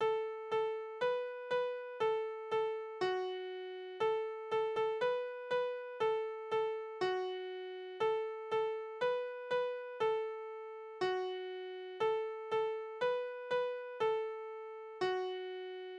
Tonart: D-Dur Taktart: 4/4 Tonumfang: Quarte Besetzung: vokal Externe Links